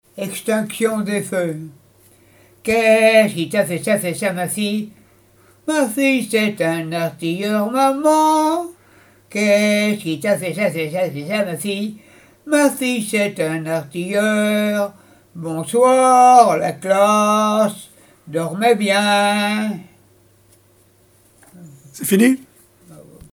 chansons traditionnelles et d'école
Pièce musicale inédite